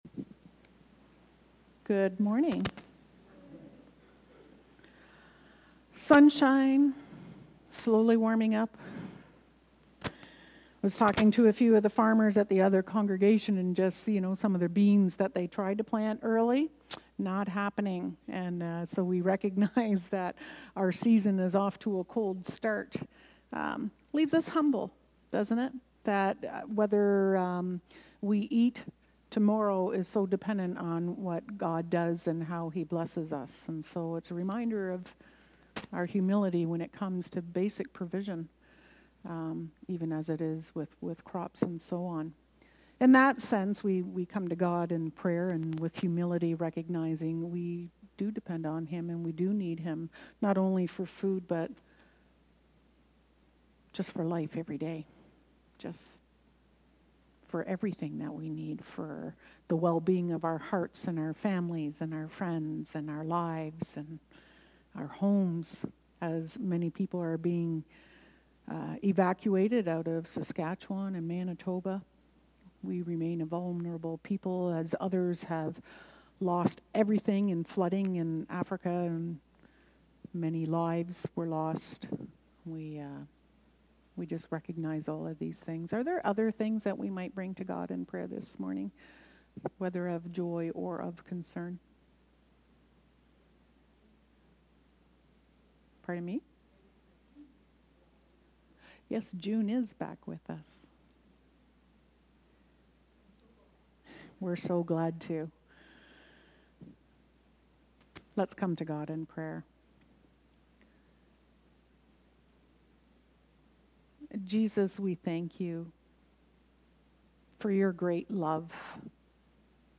Knox Binbrook worship service